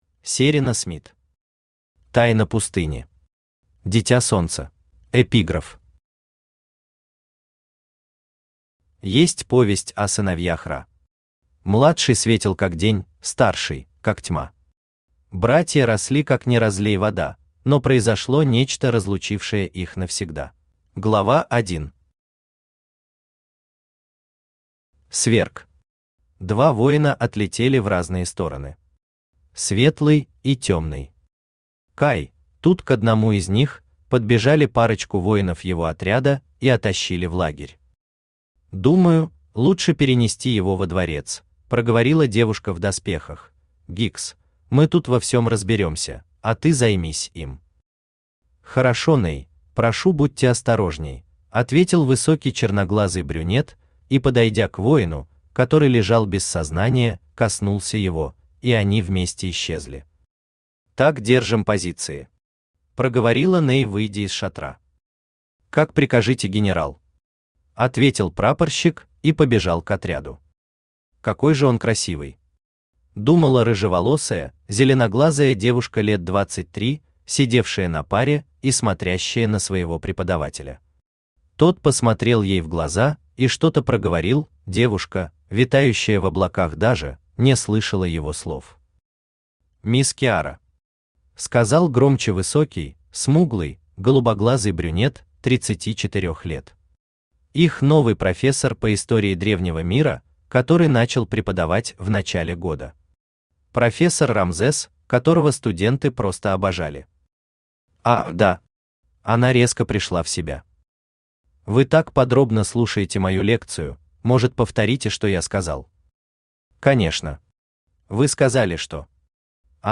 Аудиокнига Тайна пустыни. Дитя солнца | Библиотека аудиокниг
Aудиокнига Тайна пустыни. Дитя солнца Автор Серено Смит Читает аудиокнигу Авточтец ЛитРес.